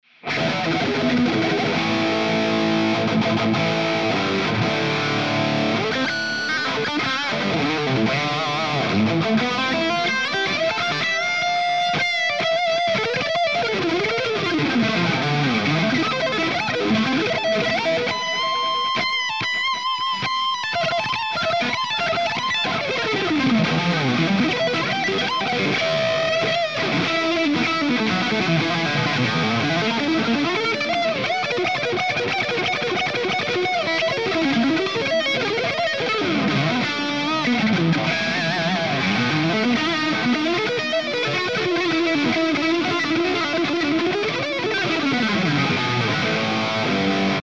It's fully loaded for any Hi-Gain application. 100 Watts of sofisticated Rock and Metal tones, It's all about gain!
Improv
RAW AUDIO CLIPS ONLY, NO POST-PROCESSING EFFECTS